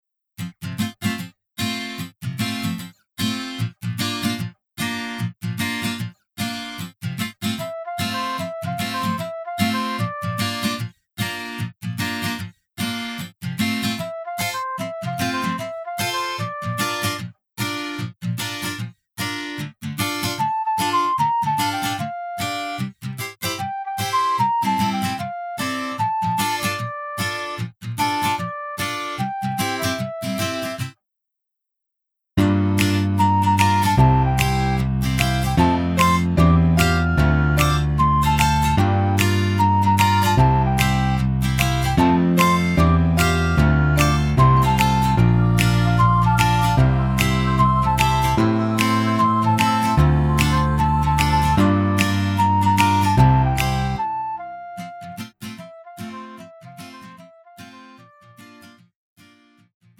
음정 여자키 2:35
장르 가요 구분 Pro MR